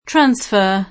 transfer [tr#nSfer; ingl.